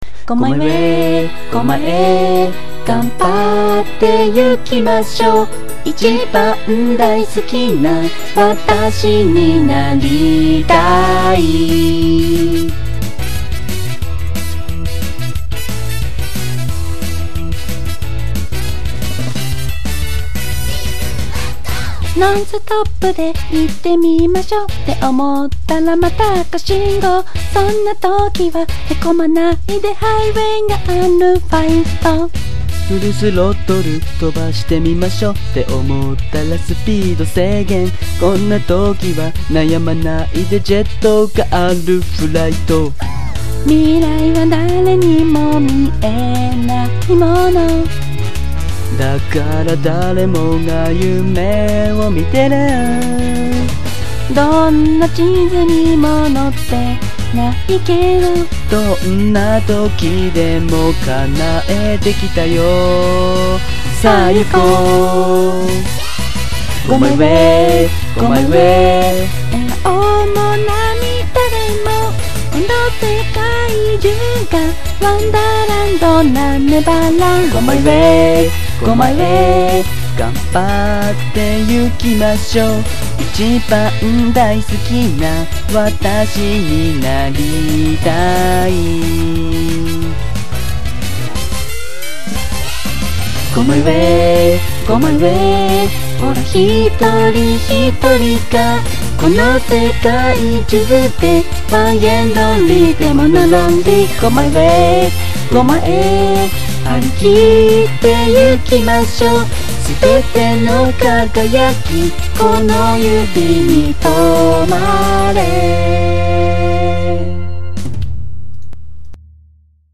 さて、今回は周りの方々に自分も便乗して歌ったので一応あげておきます。
しかも結構音汚いです。